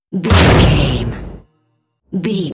Dramatic hit drum metal
Sound Effects
Atonal
heavy
intense
dark
aggressive
hits